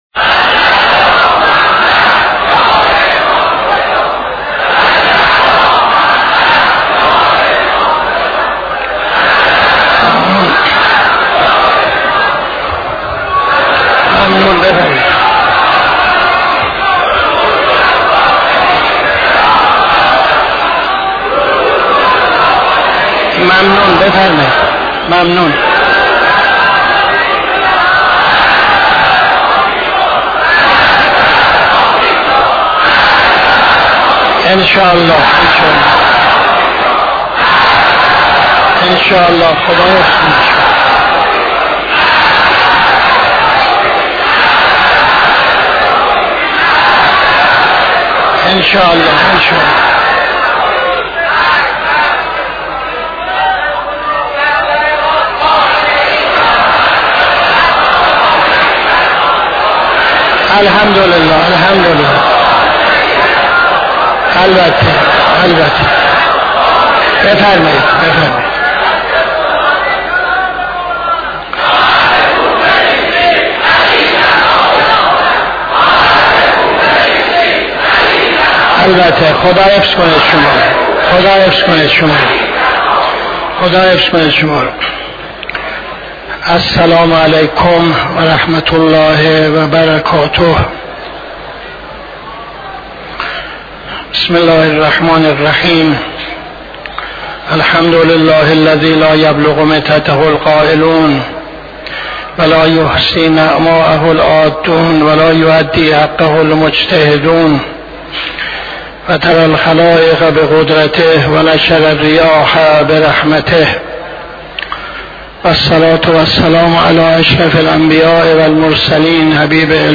خطبه اول نماز جمعه 02-11-77